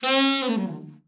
ALT FALL  17.wav